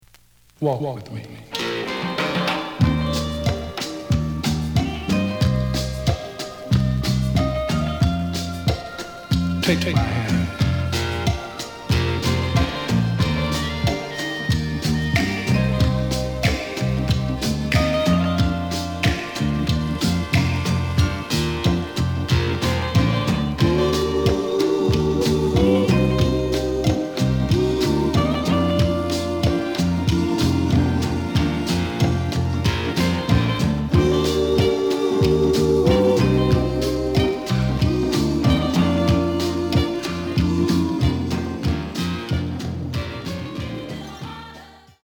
試聴は実際のレコードから録音しています。
The listen sample is recorded from the actual item.
●Format: 7 inch
●Genre: Soul, 70's Soul